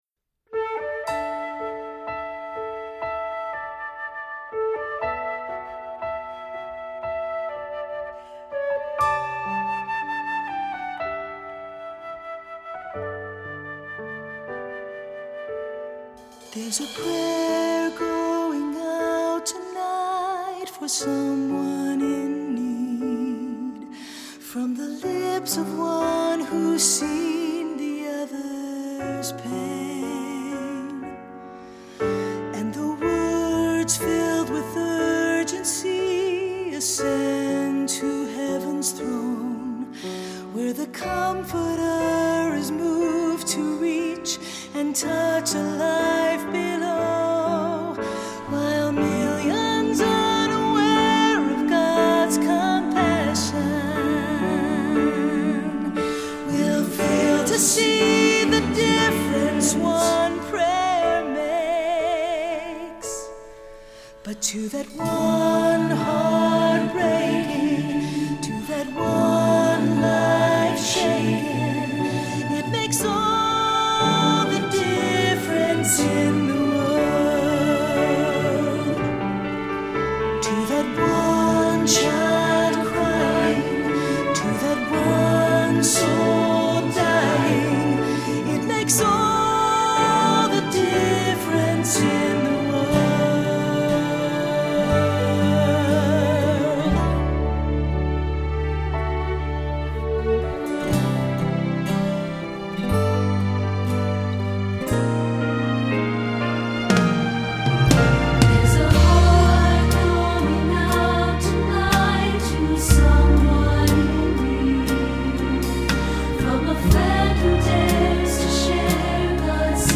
Voicing: SATB and Solo